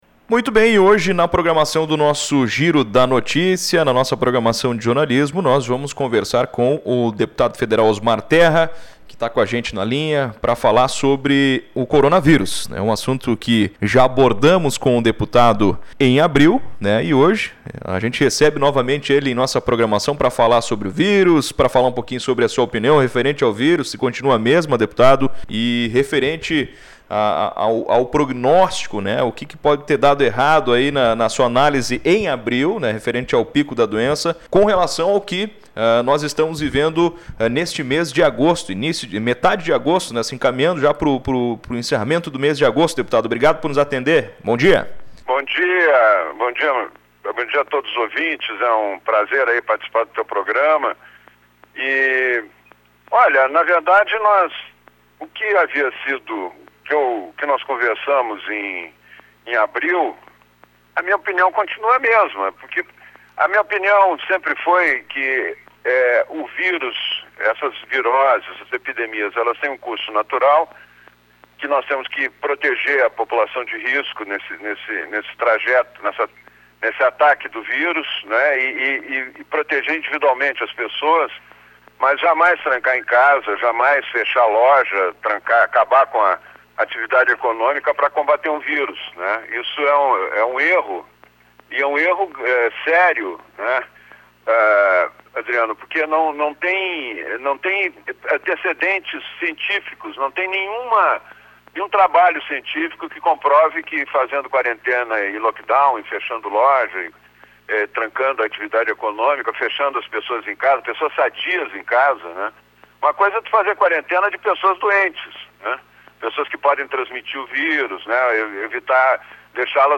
Segundo ele, que conversou com a nossa reportagem, não há nenhuma evidência científica que lockdown e quarentena diminuem o número de casos.